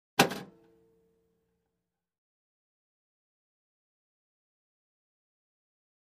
Punch | Sneak On The Lot